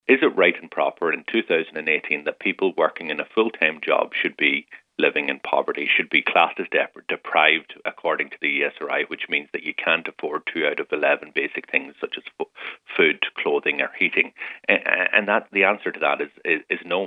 The current minimum wage is 9-Euro-60 an hour but Donegal Deputy Pearse Doherty says it’s not enough to live on: